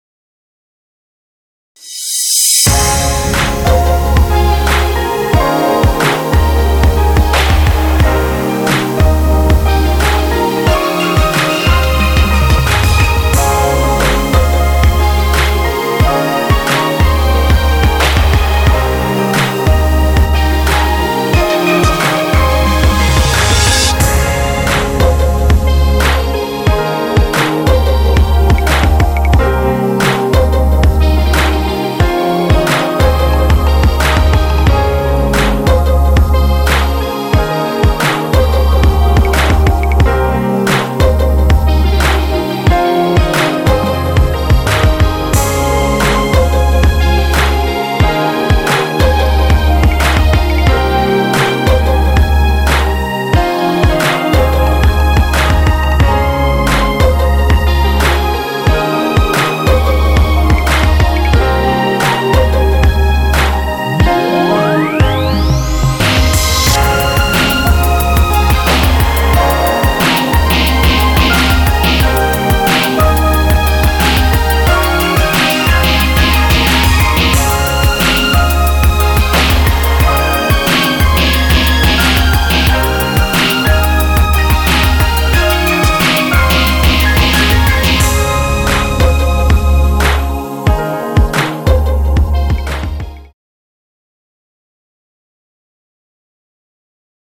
드럼이 조금 아쉽지않았나싶습니다.